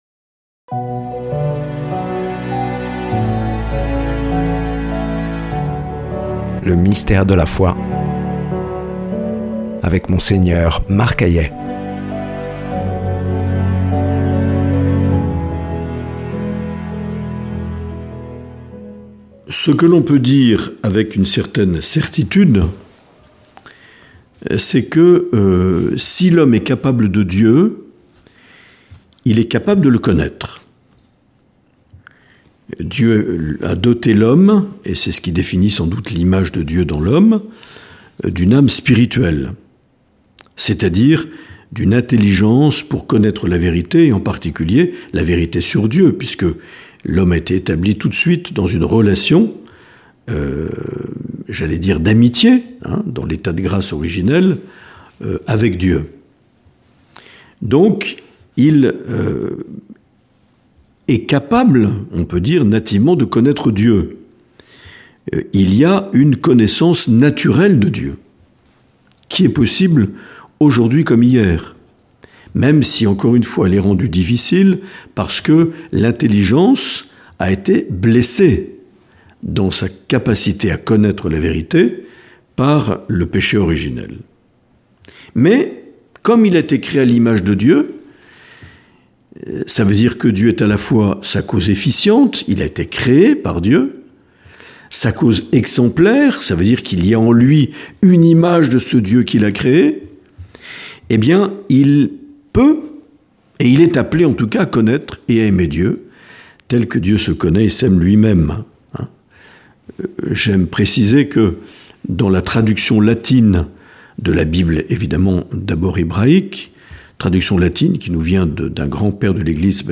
Une émission présentée par Monseigneur Marc Aillet